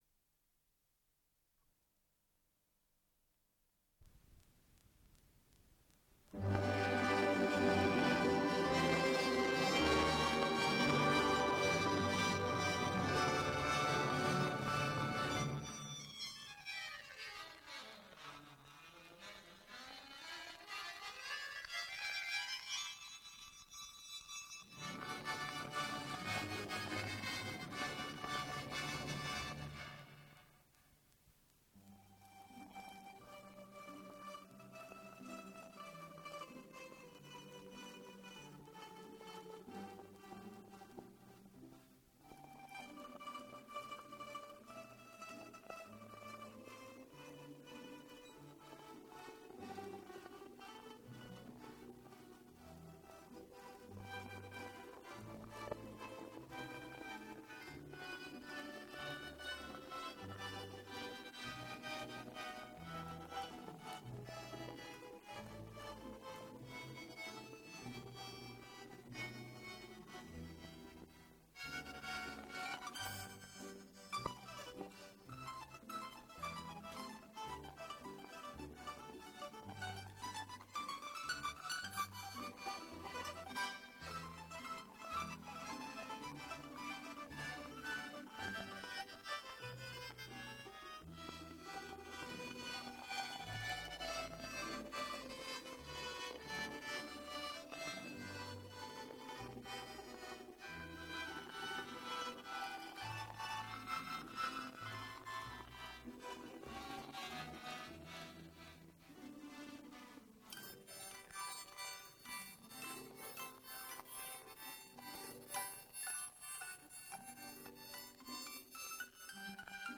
3)" Травушка -- Муравушка" Русская народная. Исполняет Молодёжный русский народный оркестр .
домры
Дубль моно